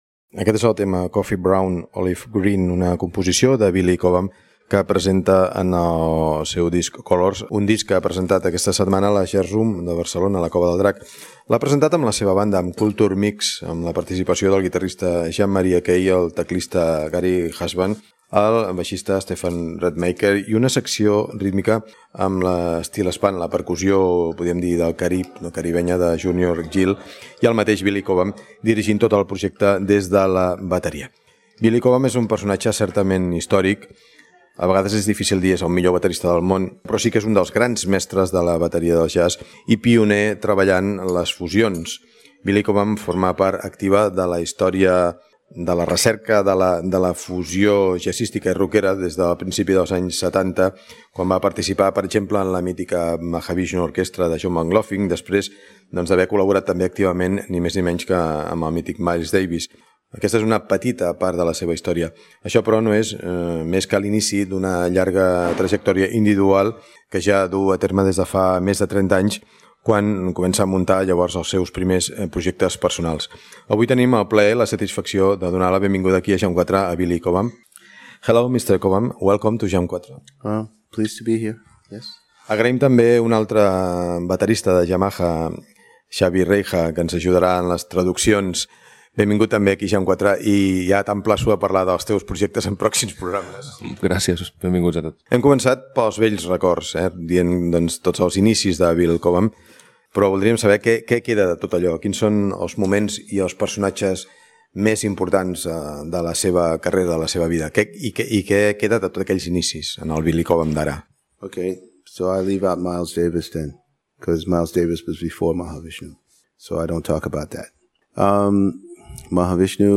Perfil biogràfic musical i entrevista al músic Billy Cobham Gènere radiofònic Musical